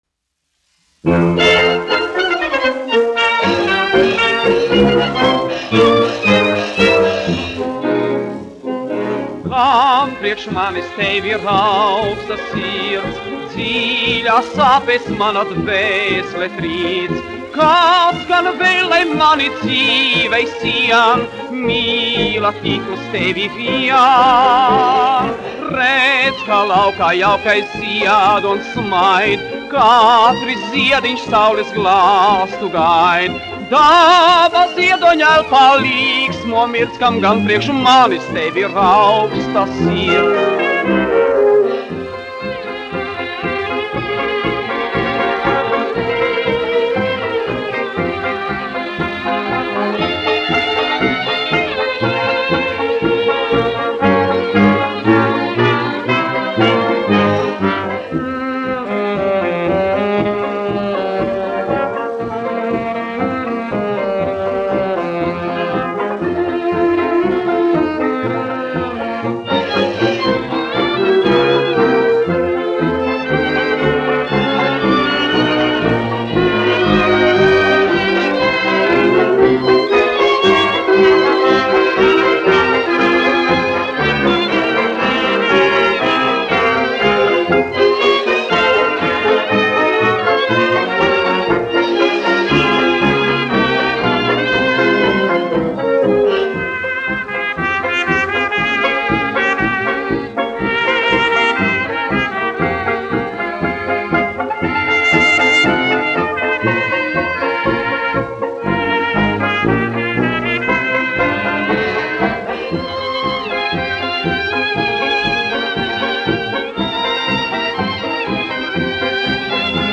dziedātājs
1 skpl. : analogs, 78 apgr/min, mono ; 25 cm
Fokstroti
Populārā mūzika
Skaņuplate